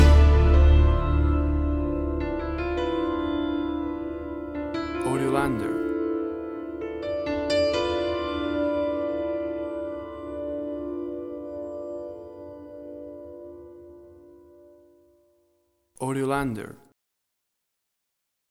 WAV Sample Rate: 16-Bit stereo, 44.1 kHz
Tempo (BPM): 112